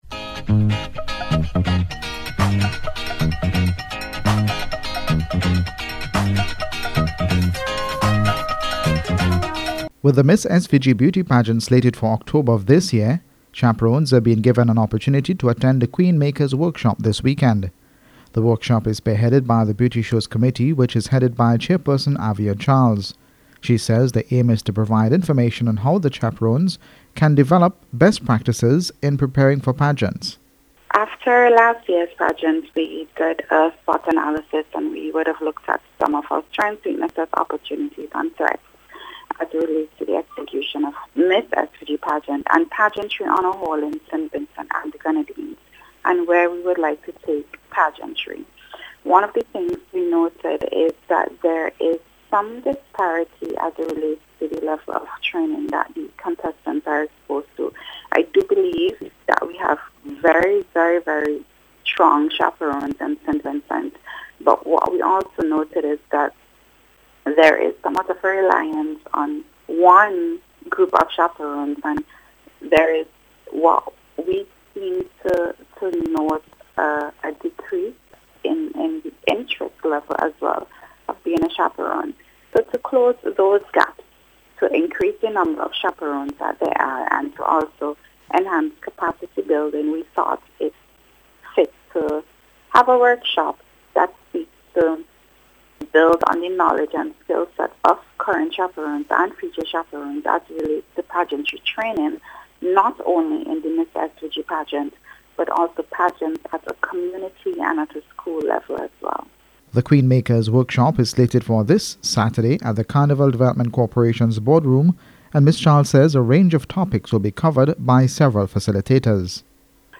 QUEEN-MAKERS-REPORT.wav